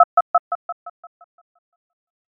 phone-ring-17.mp3